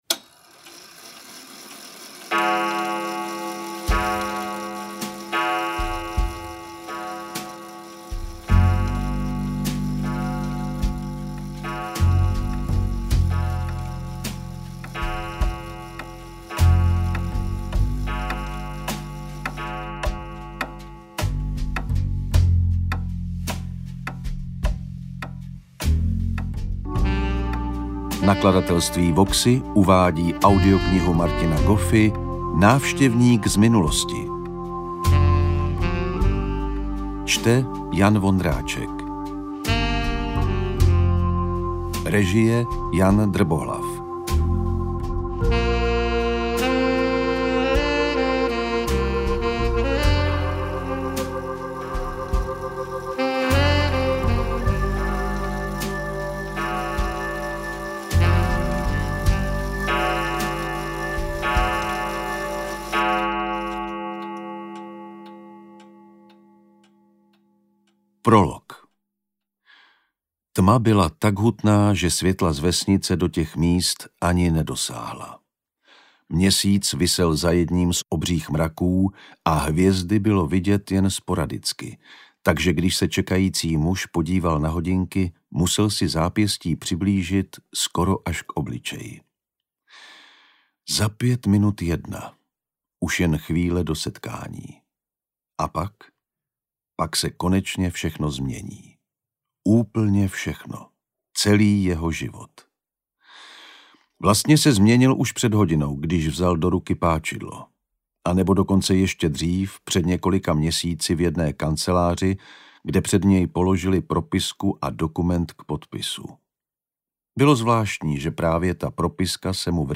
Interpret:  Jan Vondráček